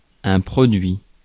Ääntäminen
France: IPA: [pʁo.dɥi]